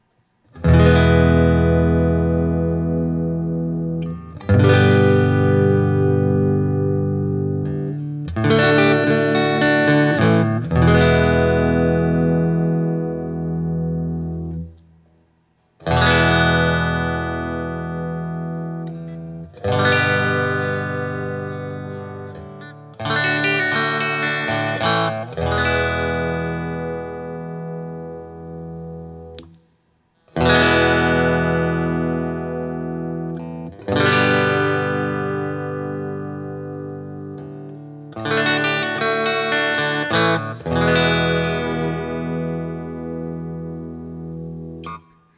Open Chords #1
As usual, for these recordings I used my normal Axe-FX Ultra setup through the QSC K12 speaker recorded into my trusty Olympus LS-10.
For each recording I cycled through the neck pickup, both pickups, and finally the bridge pickup.